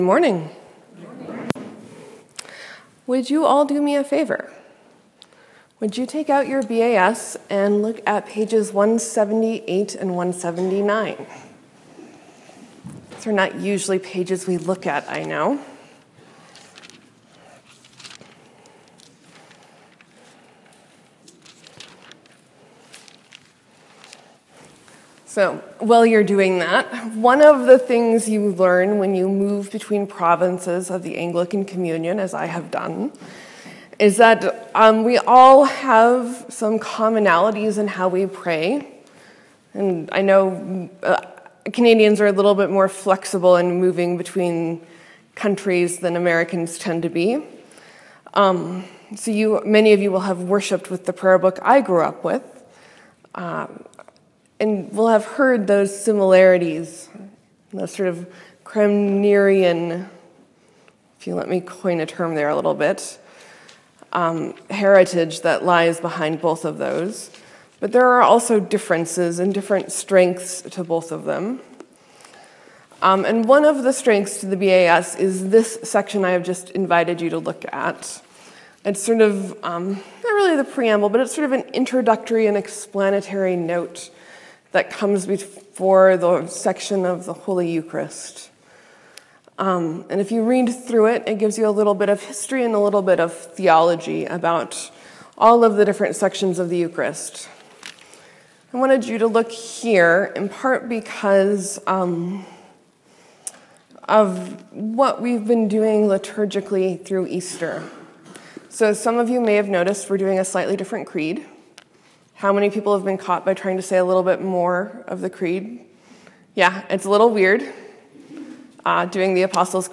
Sermon: Where did Philip come from?